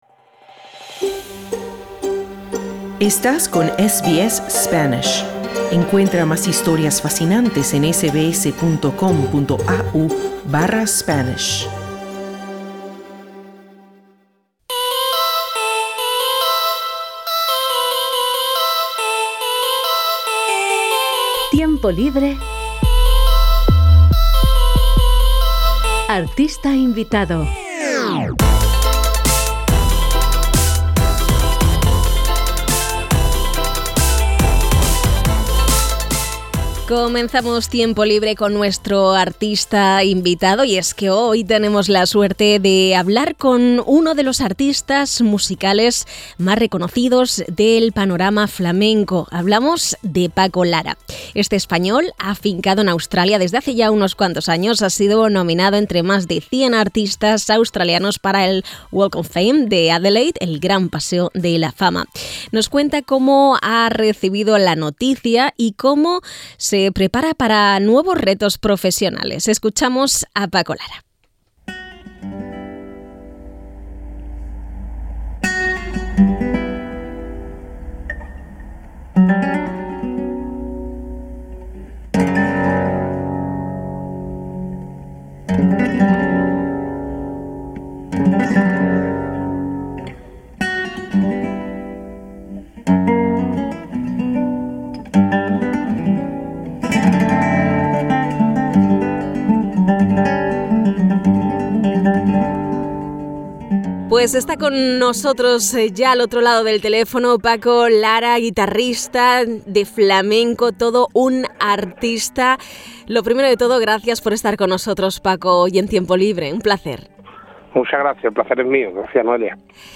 El español nos habla de su nominación, sus nuevos proyectos profesionales y del flamenco en Australia.